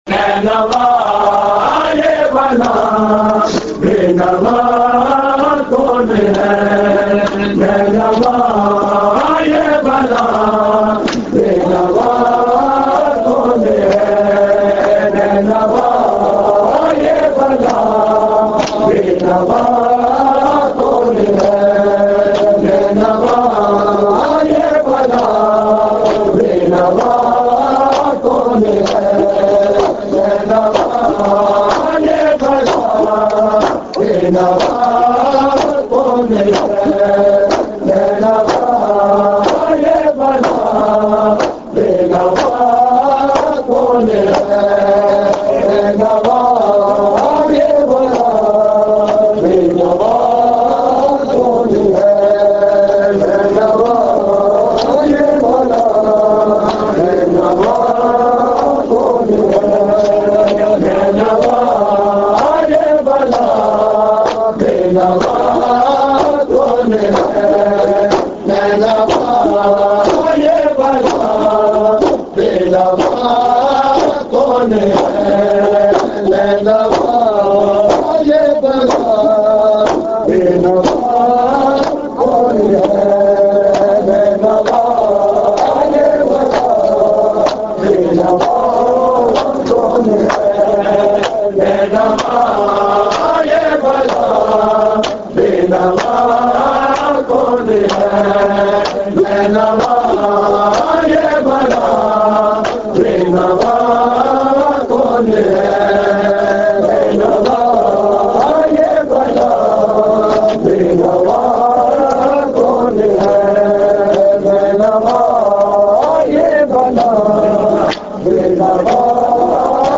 Markazi Matmi Dasta, Rawalpindi
Recording Type: Live